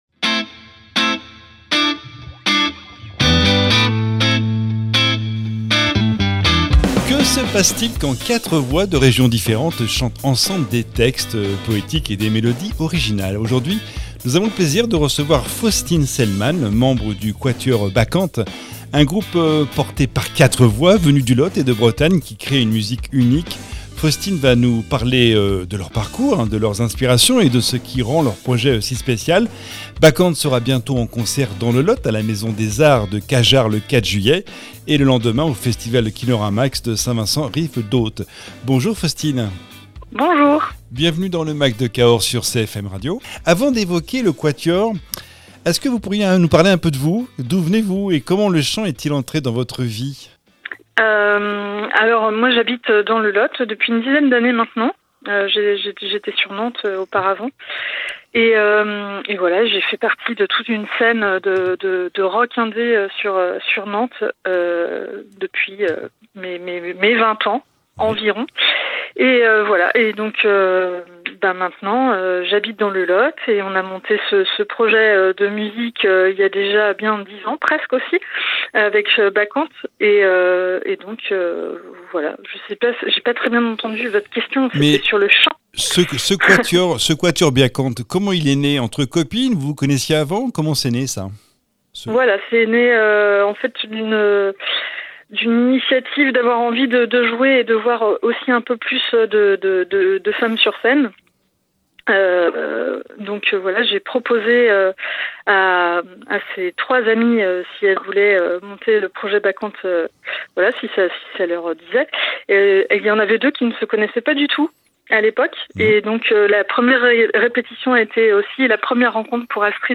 membre du quatuor